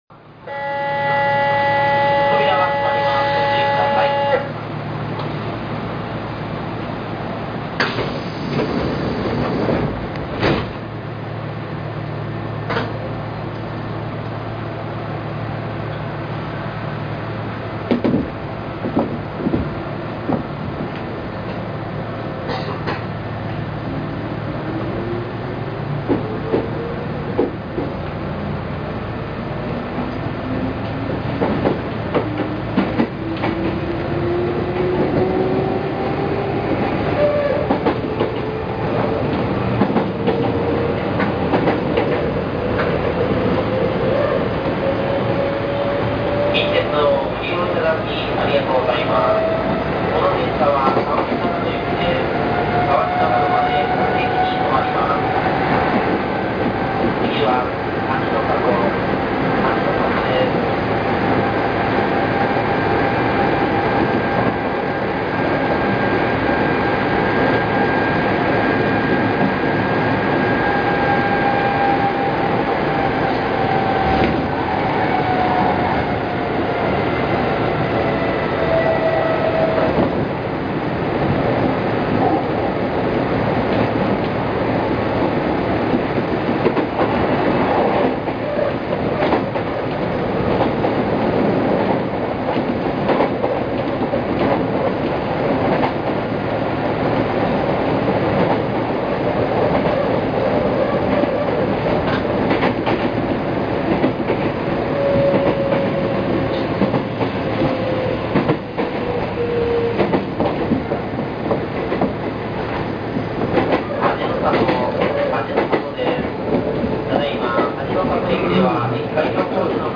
〜車両の音〜
・6200系走行音
【南大阪線】藤井寺〜土師ノ里（2分38秒：1.20MB）
ごく普通の抵抗制御車の走行音。
高速走行時はなかなかいい音を出します。
6200_Fujiidera-Hajinosato.mp3